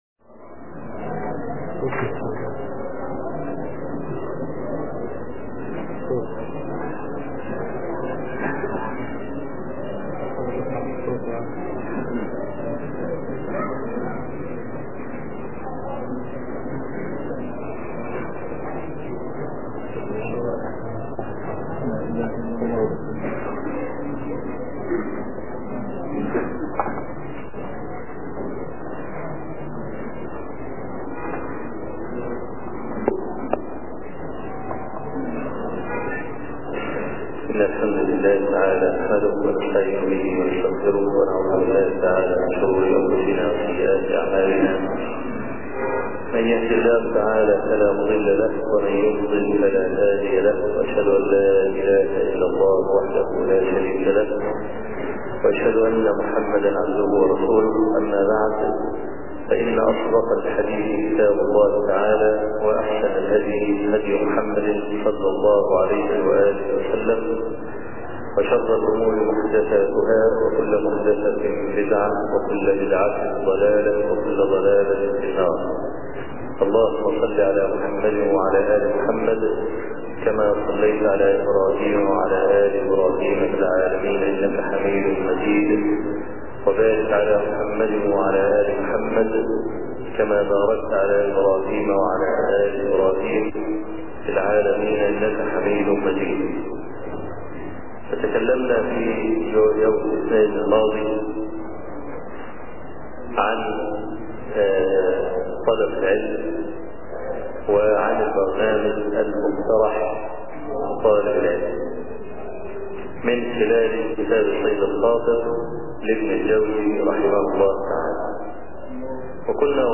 منطلقات طالب العلم (دروس المساجد) - الشيخ أبو إسحاق الحويني